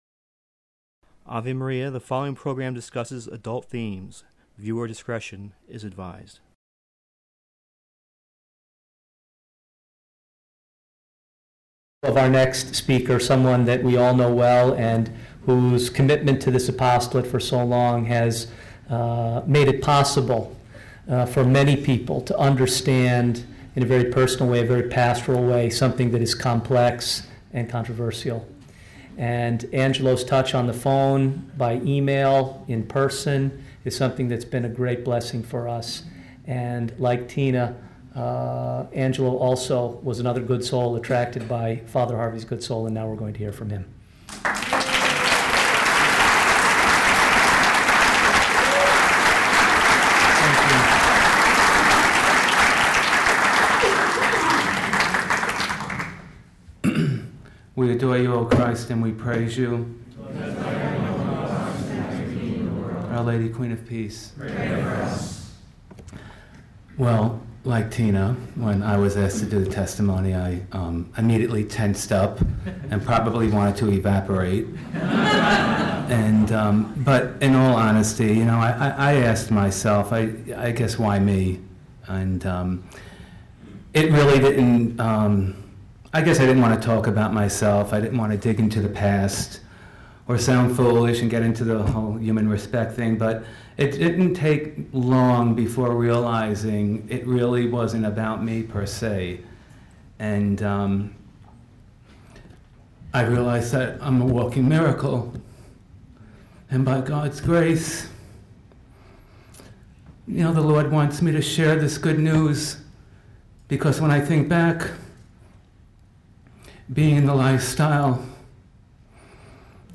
Recorded at the the annual National Courage Conference in the Chicago area.